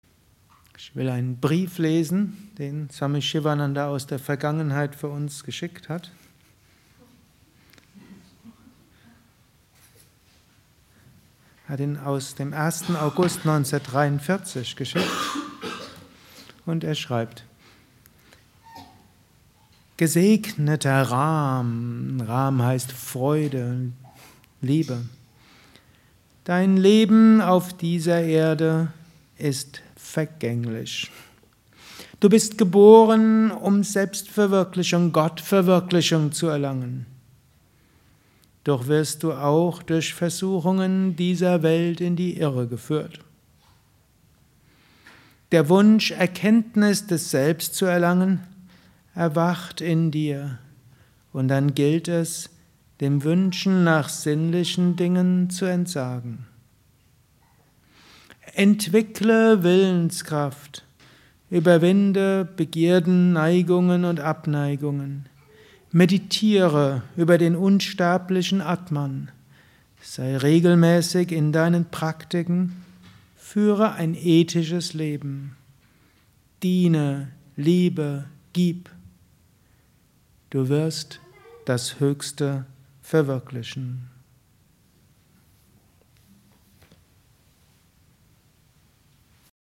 Gelesen im Anschluss nach einer Meditation im Haus Yoga Vidya Bad Meinberg.
Lausche einem Vortrag über: Du Gesegneter